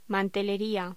Locución: Mantelería
voz